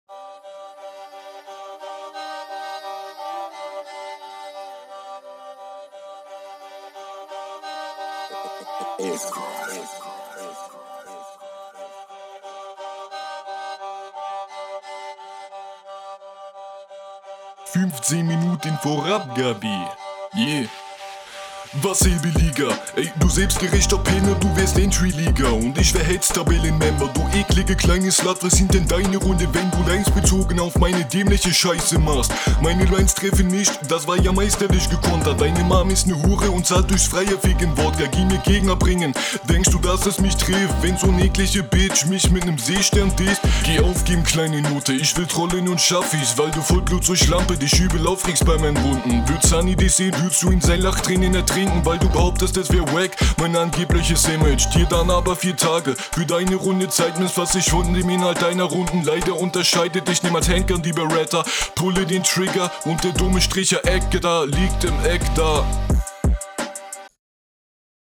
Flow sitzt nicht so richtig.
Beat ist zu leise, kommst auch nicht wirklich drauf klar.